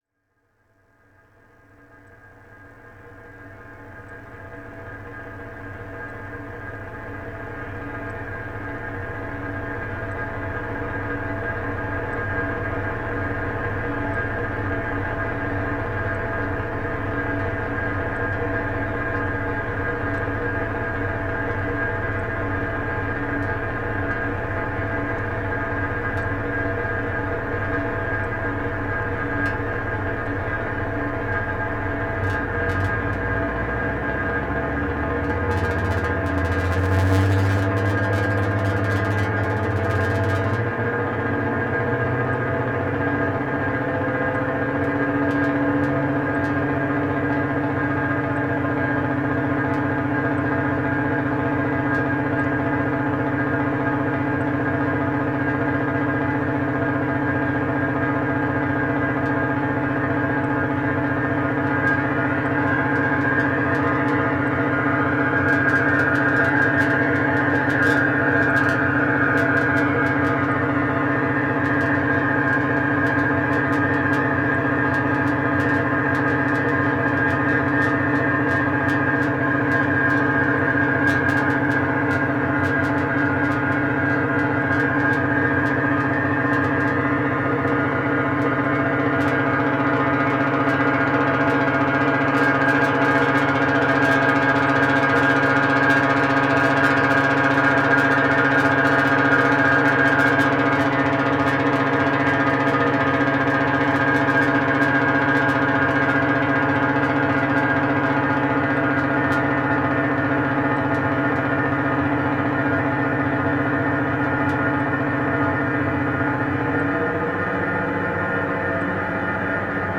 Electro Expérimental